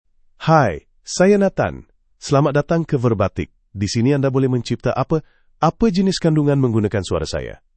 Nathan — Male Malayalam (India) AI Voice | TTS, Voice Cloning & Video | Verbatik AI
MaleMalayalam (India)
Nathan is a male AI voice for Malayalam (India).
Voice sample
Male